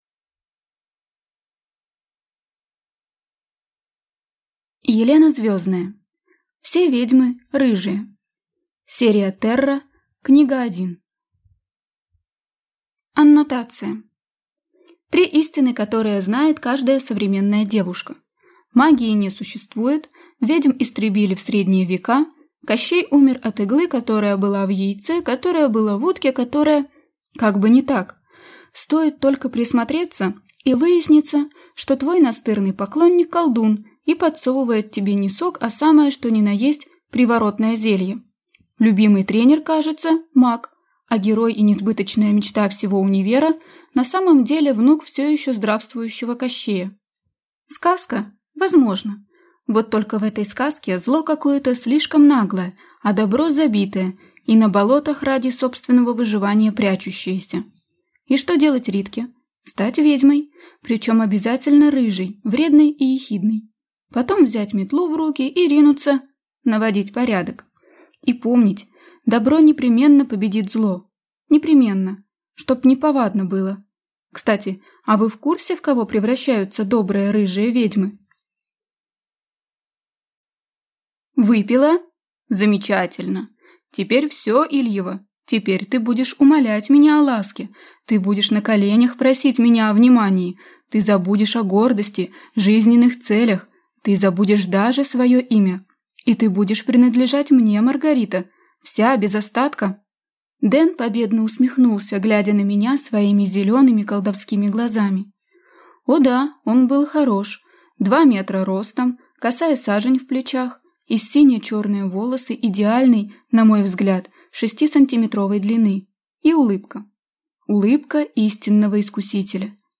Аудиокнига Все ведьмы – рыжие - купить, скачать и слушать онлайн | КнигоПоиск